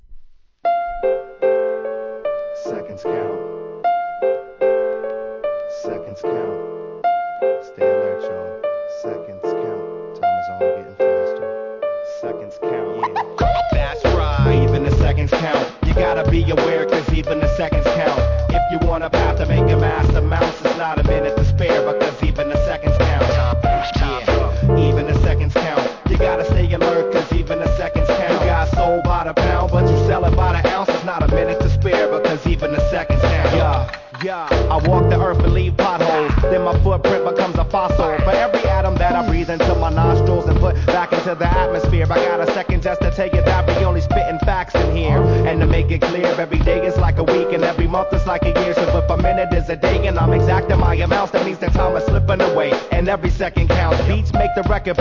HIP HOP/R&B
2004年、ジャジー・アトランタ・アンダーグランド！